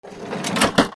レジ 閉める
/ M｜他分類 / L10 ｜電化製品・機械